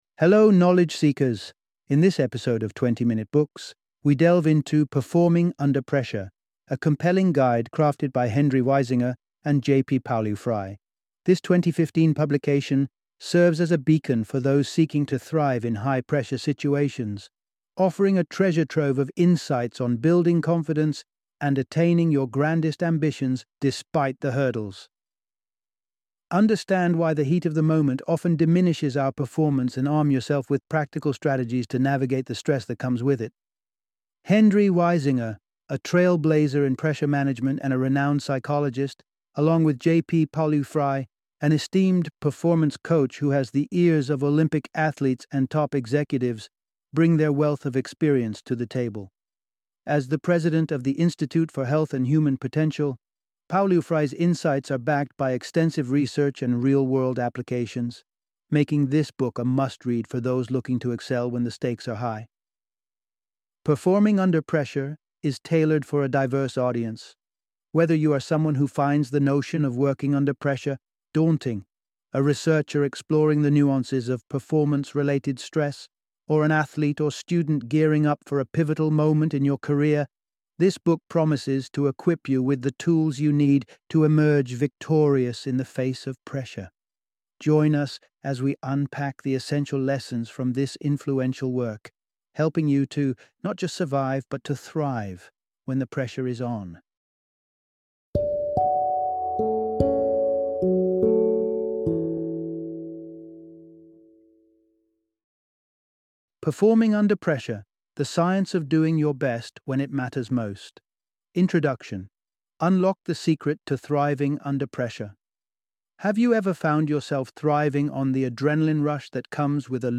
Performing Under Pressure - Audiobook Summary